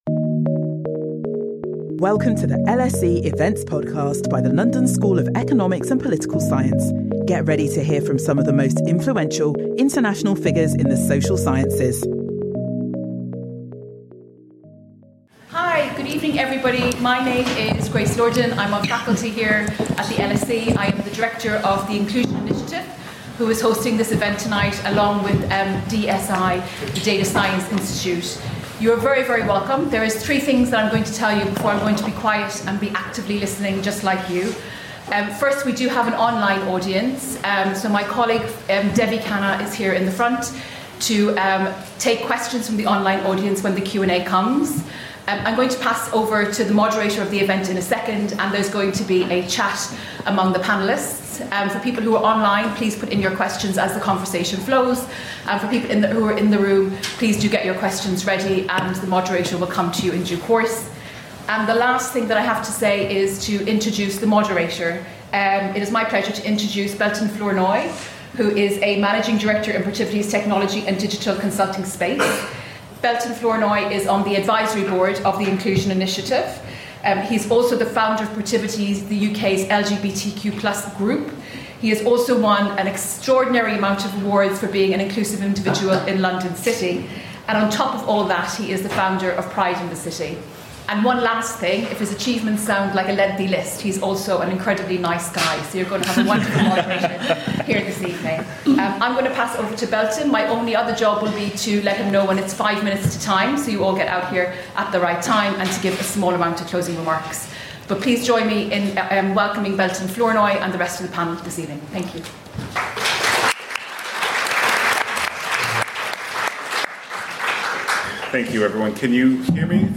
This event will navigate the complexities of AI implementation in the workplace and examine how these technologies are being developed to benefit society while challenging traditional work experiences. The event will feature conversations on the latest advancements, challenges, and ethical considerations in AI development, emphasising reducing bias and supporting diverse communities. Participants can interact with panellists during a Q&A session, fostering a deeper understanding of how AI can drive positive change.